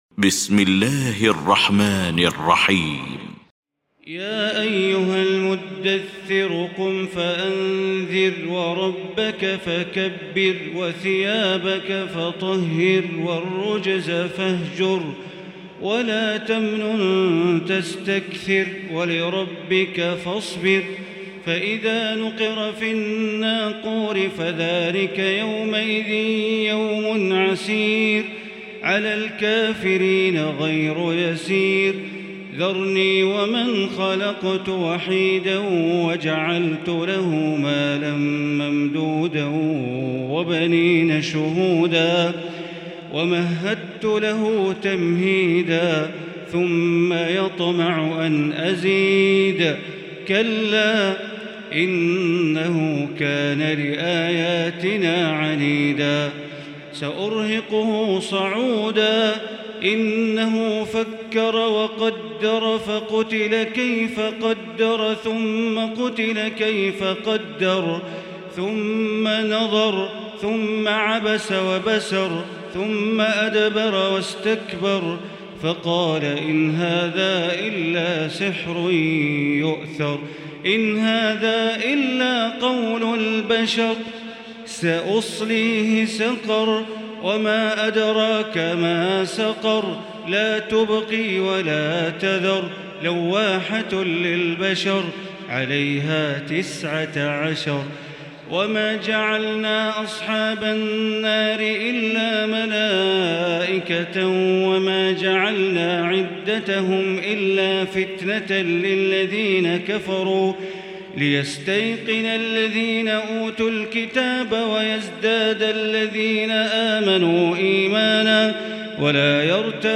المكان: المسجد الحرام الشيخ: معالي الشيخ أ.د. بندر بليلة معالي الشيخ أ.د. بندر بليلة المدثر